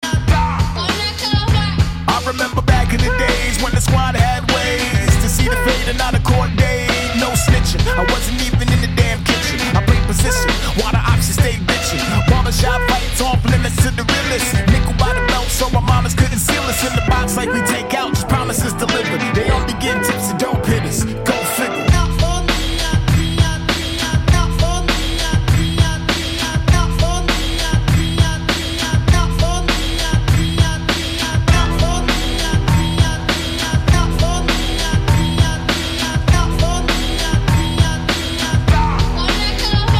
breakbeat
хип-хоп
Рэп рингтоны